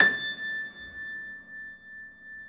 53c-pno21-A4.wav